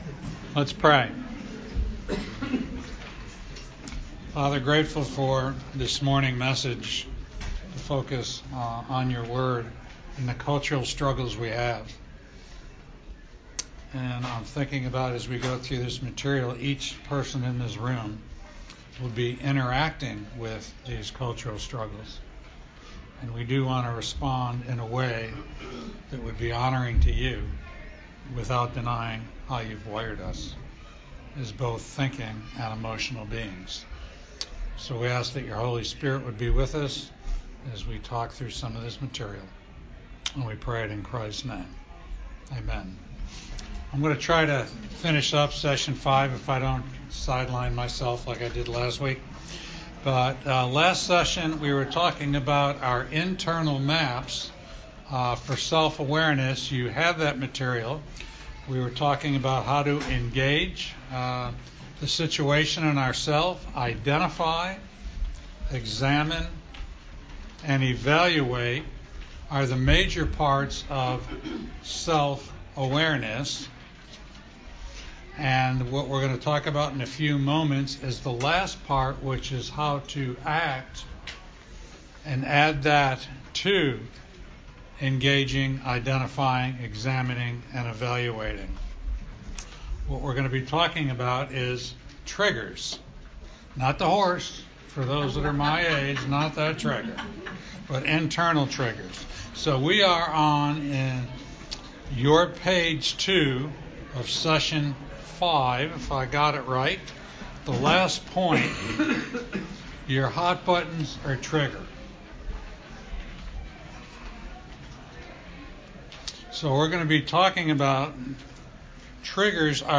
Portions of the audio have been edited during times of class interaction due to low sound quality.